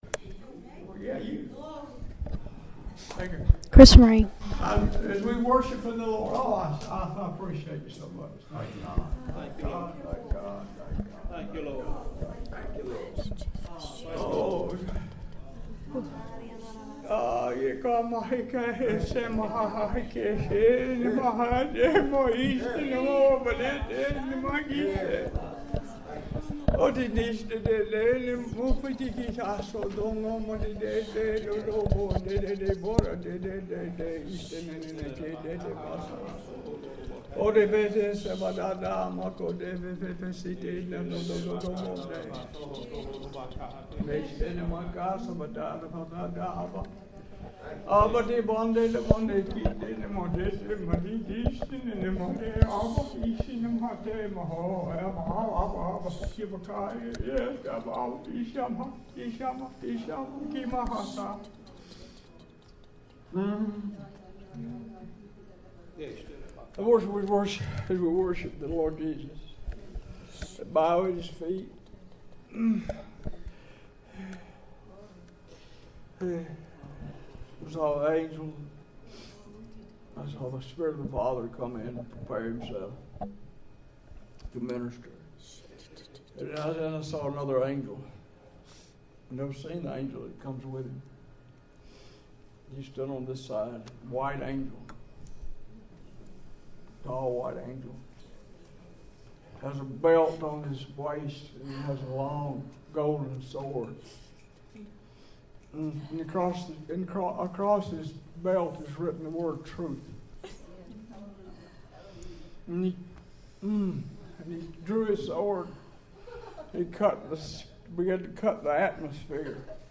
This word came in a vision and was followed by a Prophetic Song